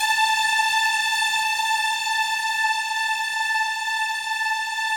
BIGORK.A4 -L.wav